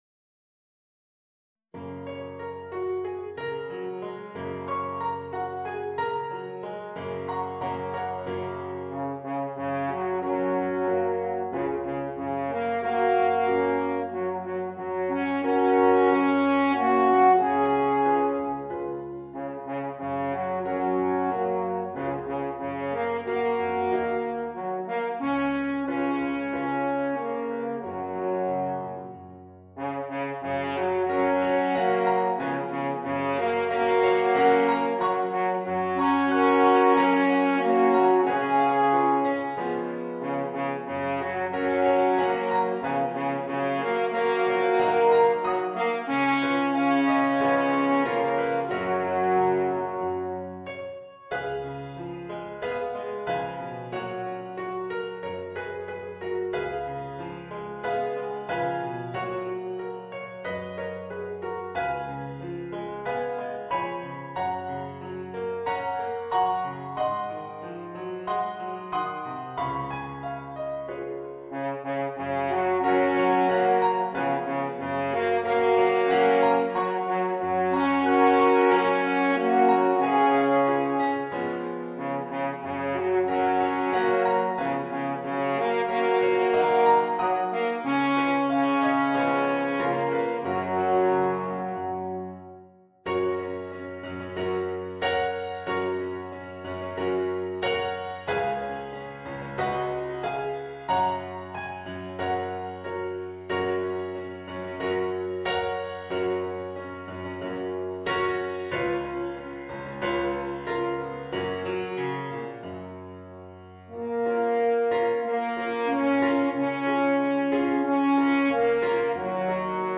Voicing: 2 Alphorns